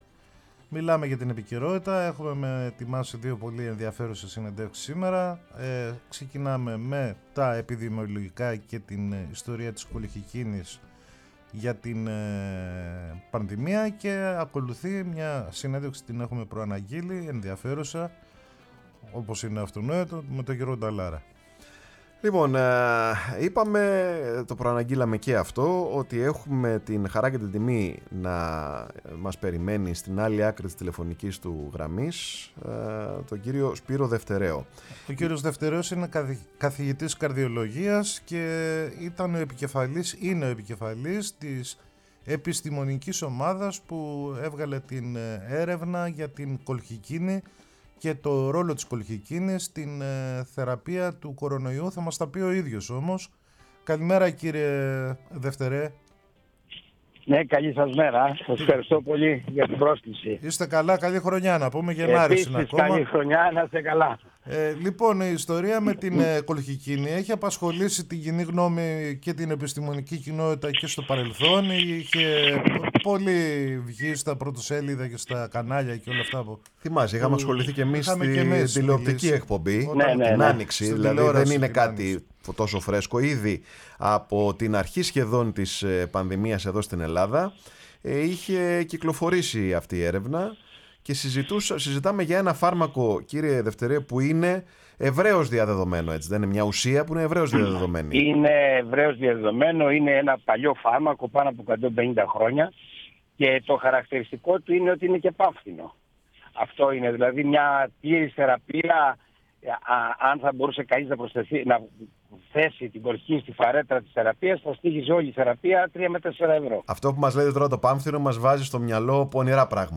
στη συνέντευξη που έδωσε, αποκλειστικά στη Φωνή της Ελλάδας